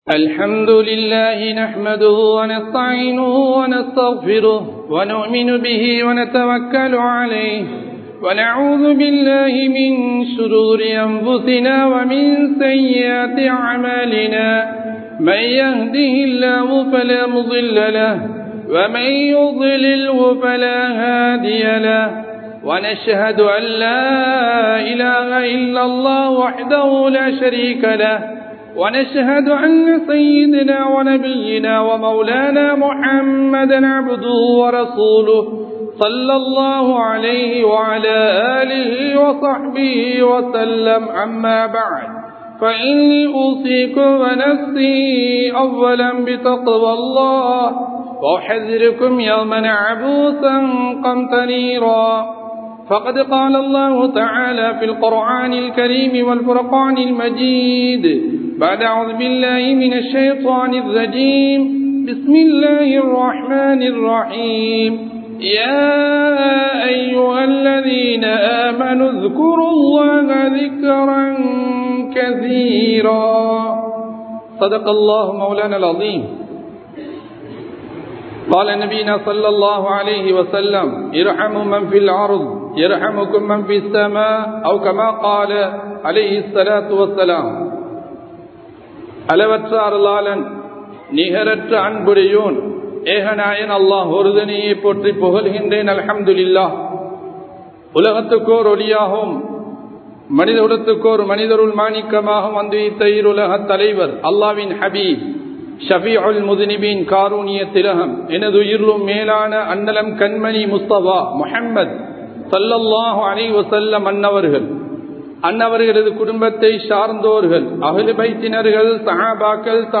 நபிமார்களுக்கு ஏற்பட்ட சோதனைகள் | Audio Bayans | All Ceylon Muslim Youth Community | Addalaichenai